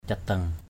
/ca-d̪ʌŋ/ (d.) sông Đa Nhim = le Danhim (rivière de Dran).